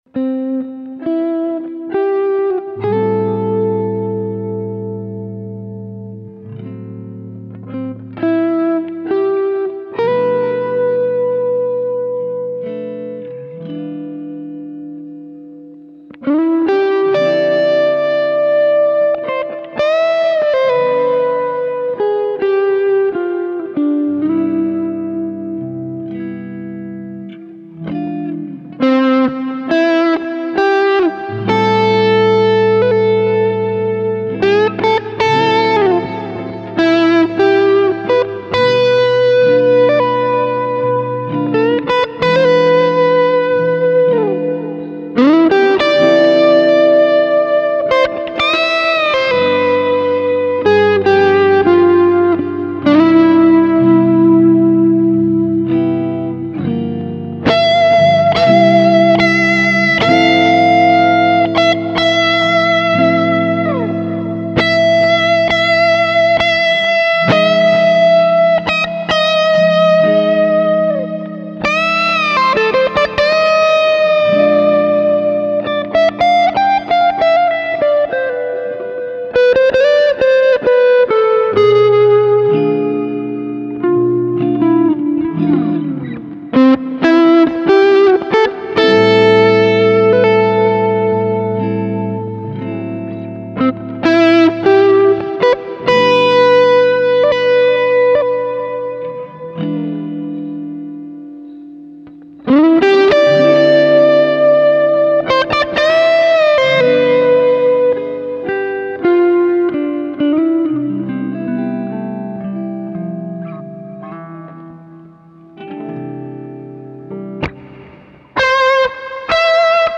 보컬, 기타
드럼